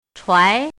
chinese-voice - 汉字语音库
chuai2.mp3